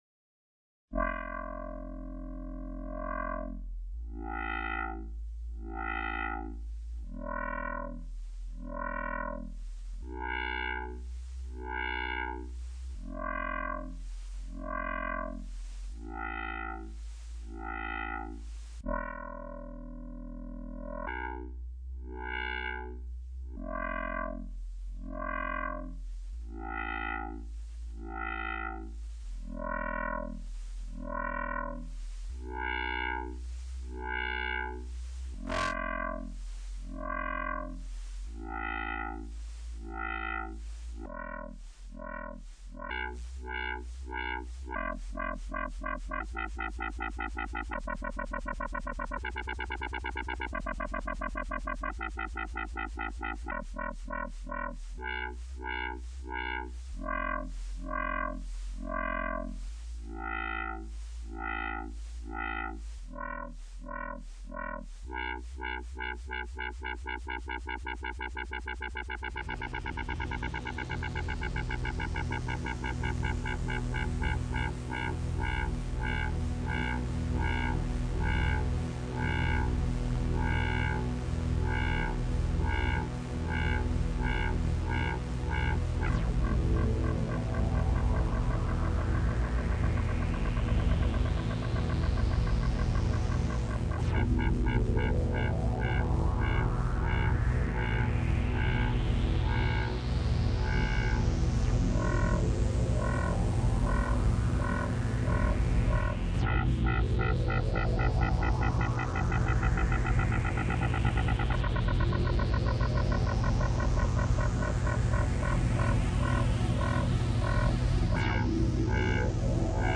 Silly sound exploration